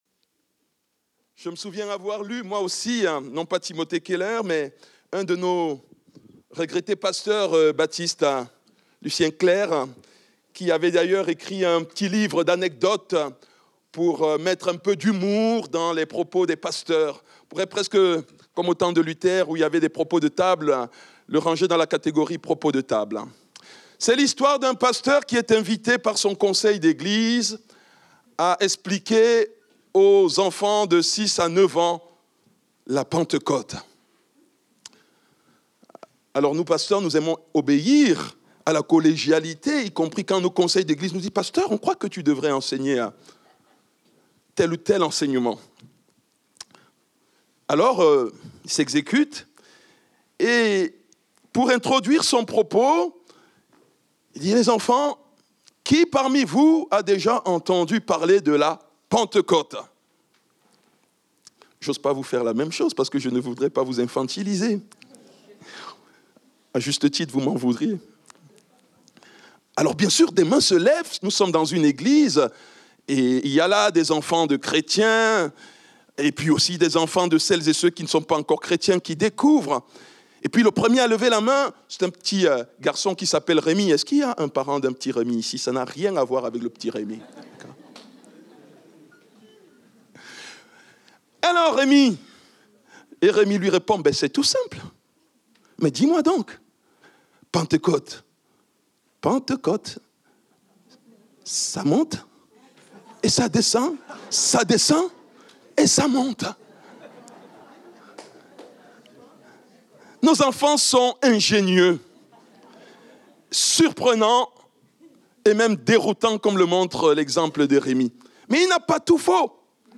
culte de pentecôte, prédication